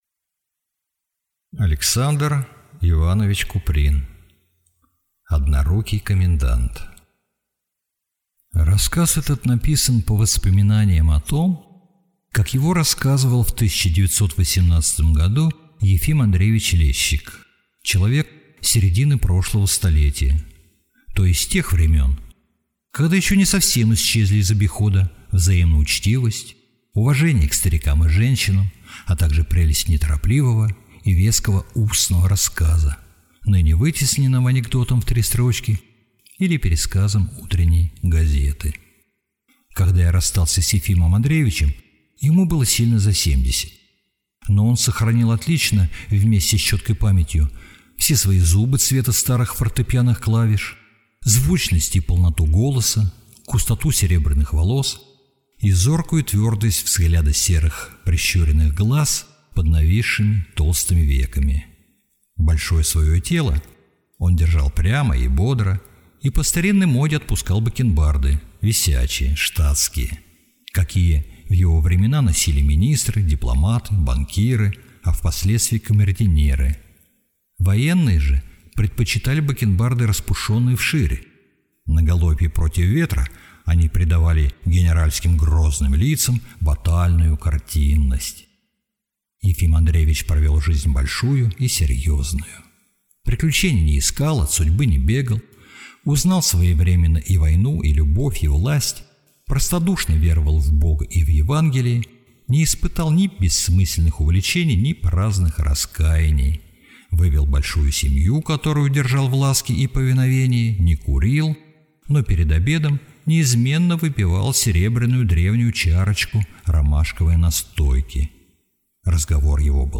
Аудиокнига Однорукий комендант | Библиотека аудиокниг